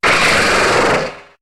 Cri de Zoroark dans Pokémon HOME.